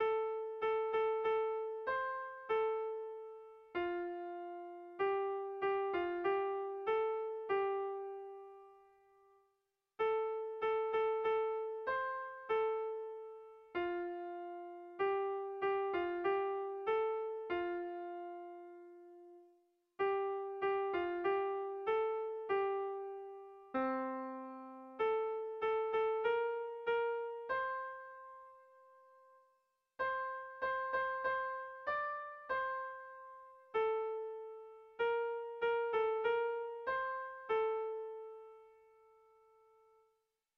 Zortziko txikia (hg) / Lau puntuko txikia (ip)
AABA2